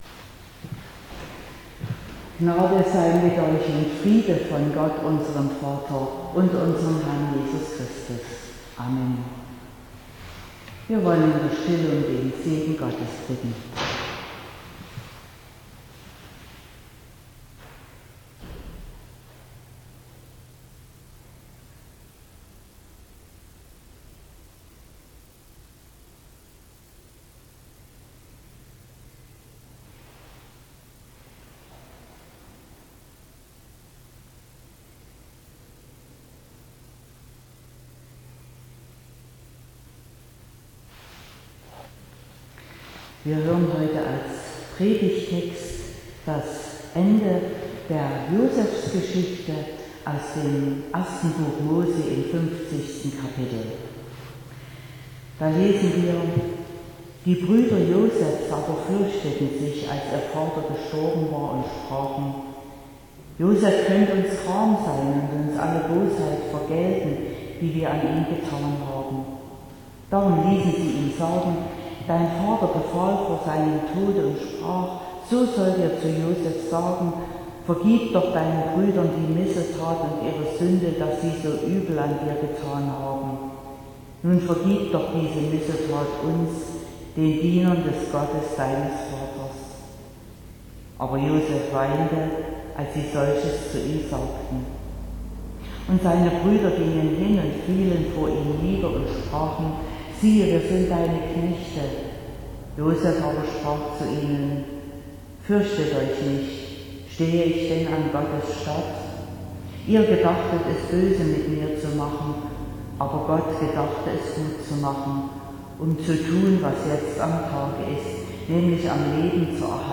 04.07.2021 – Gottesdienst
Predigt und Aufzeichnungen